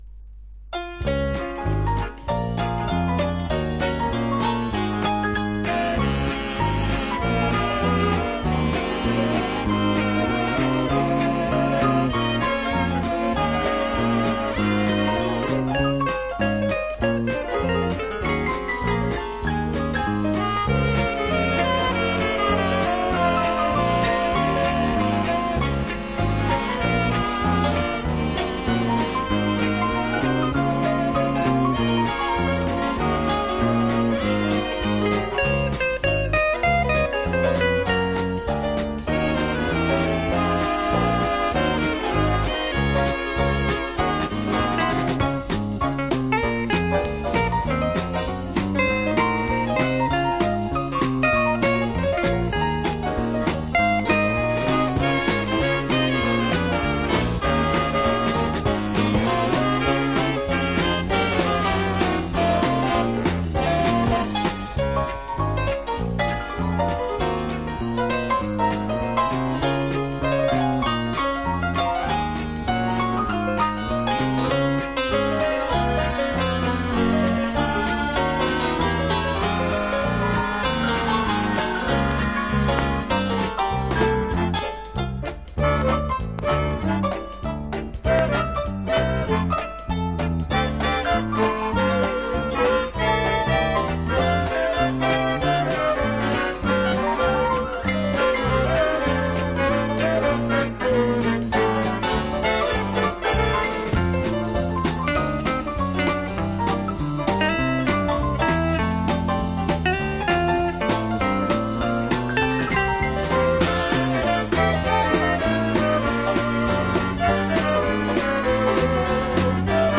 Жанр: Big Band